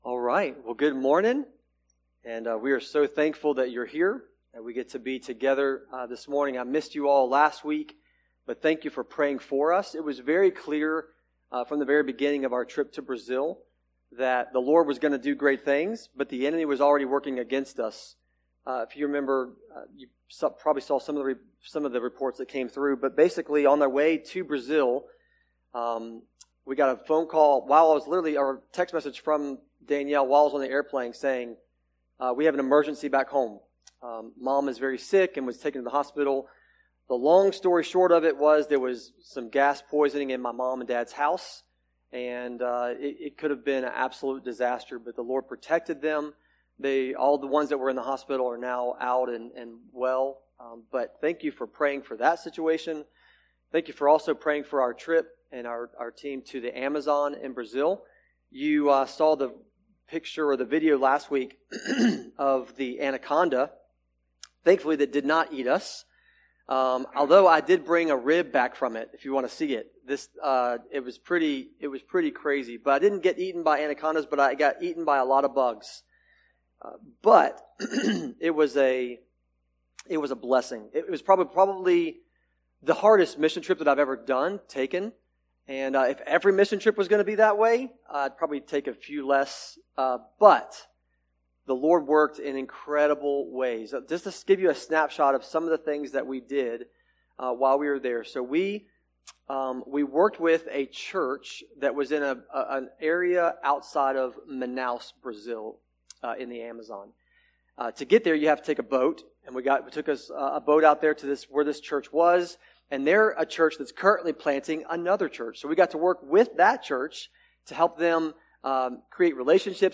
sermon-audio-trimmed-4.mp3